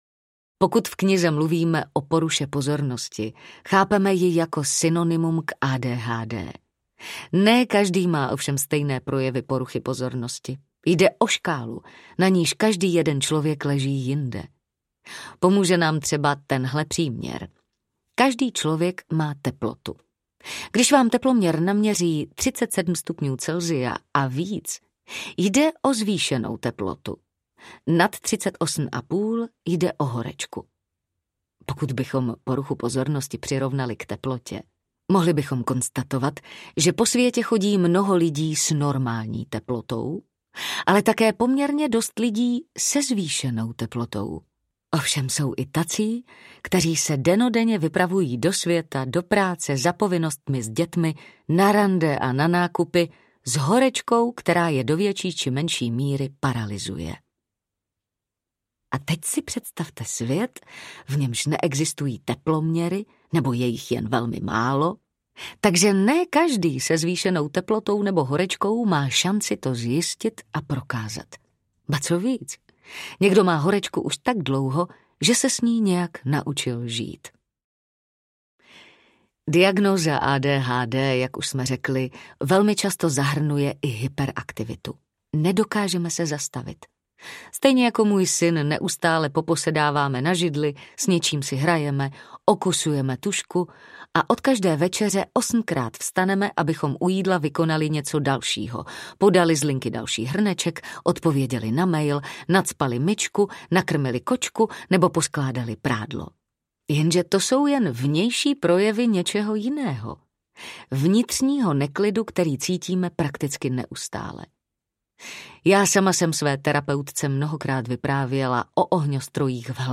Roztěkané: Jak se žije ženám s ADHD audiokniha
Ukázka z knihy
Vyrobilo studio Soundguru.